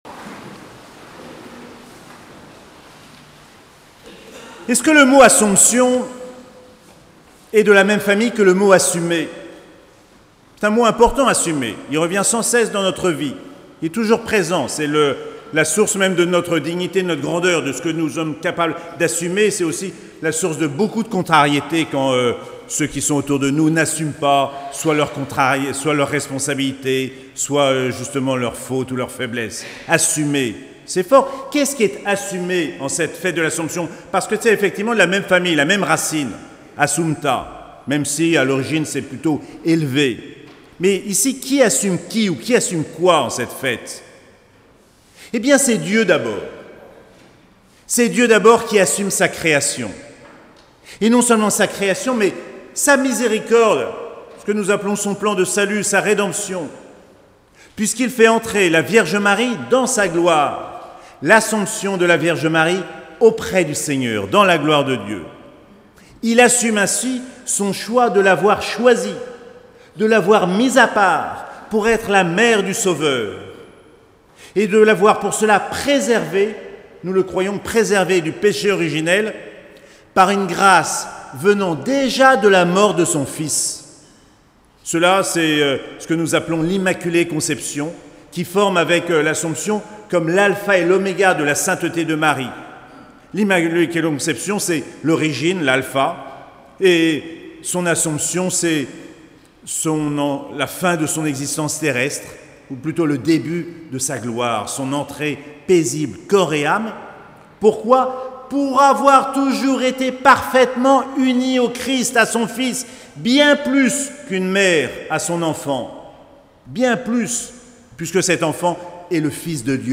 Assomption de la Vierge Marie - Lundi 15 août 2022